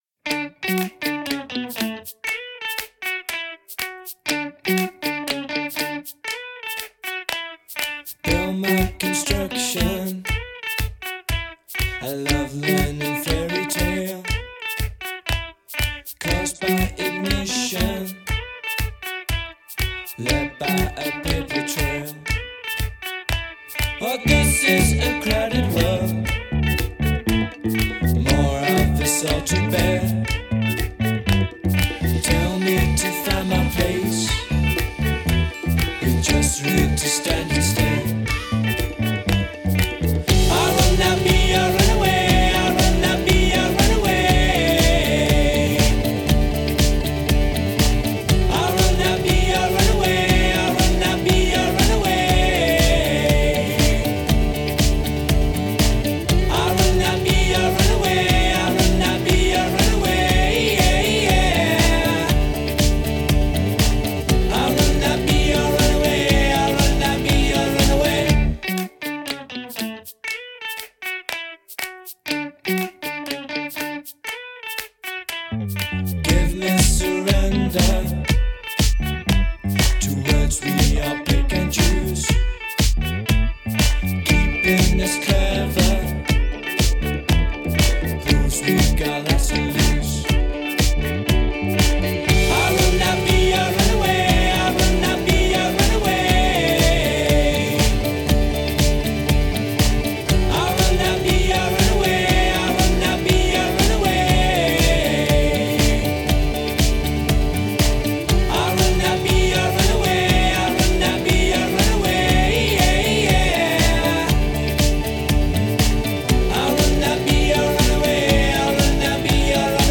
duo britannique